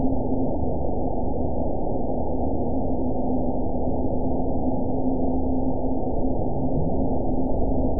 event 911426 date 02/26/22 time 22:46:42 GMT (3 years, 2 months ago) score 8.30 location TSS-AB01 detected by nrw target species NRW annotations +NRW Spectrogram: Frequency (kHz) vs. Time (s) audio not available .wav